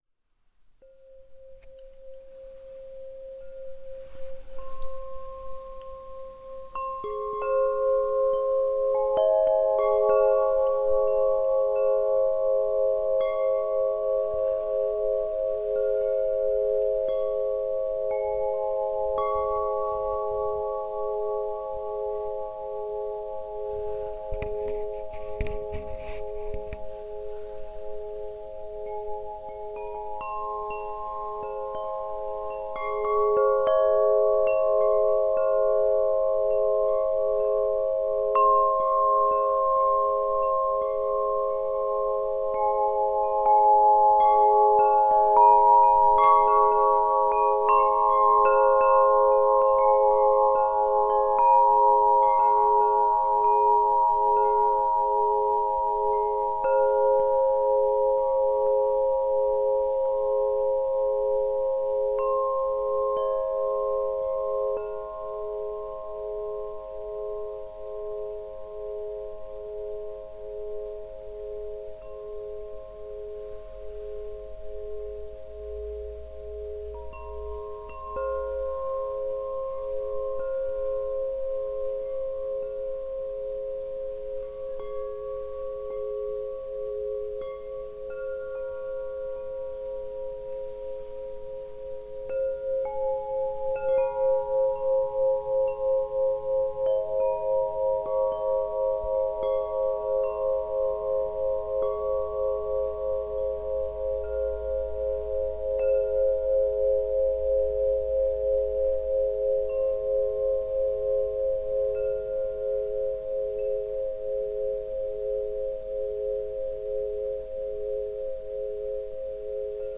Der grosse Traumtänzer führt Dich noch weicher und sinnlicher in die Bereiche Deines Seins, die Du nicht greifen kannst, möchte Dich hinter die äußere Erscheinung Deiner sichtbaren Welt führen.
Beschreibung Klangspiel-Komposition: Der grosse “ TRAUMTÄNZER „ Tanze deinen Lebenstraum Ich kann Dich nicht sehen, kann Dich nicht fassen, aber Du bist da.